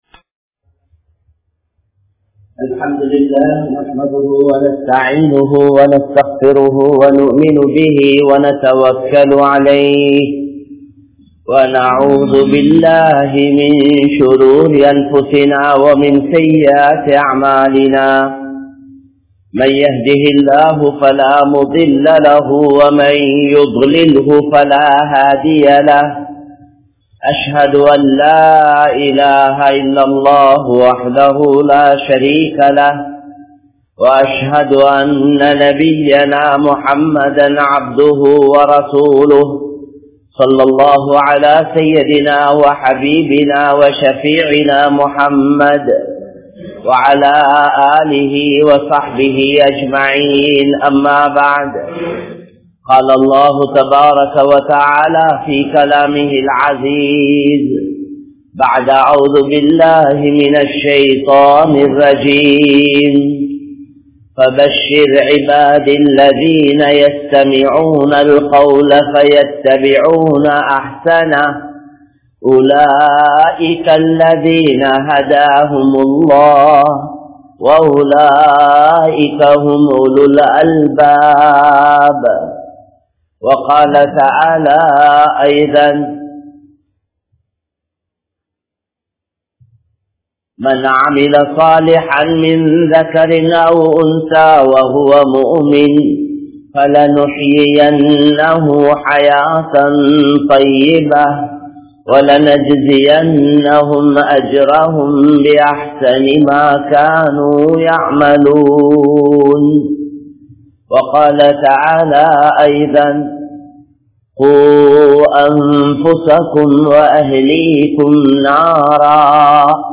Suvarkat`thitkuriya Pengal(சுவர்க்கத்திற்குரிய பெண்கள்) | Audio Bayans | All Ceylon Muslim Youth Community | Addalaichenai